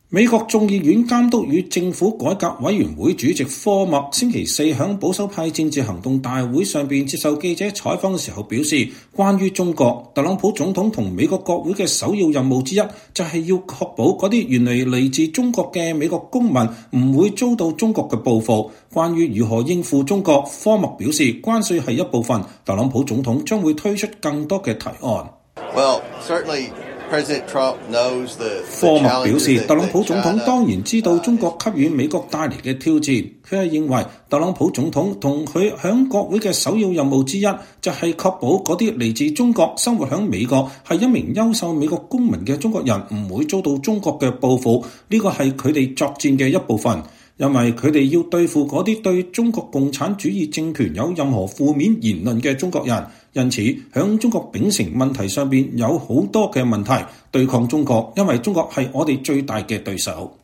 美國眾議院監督與政府改革委員會主席科默(James Comer)週四在保守派政治行動大會接受記者採訪時表示，關於中國，特朗普總統和美國國會的首要任務之一就是確保那些原本來自中國的美國公民不會遭中國報復。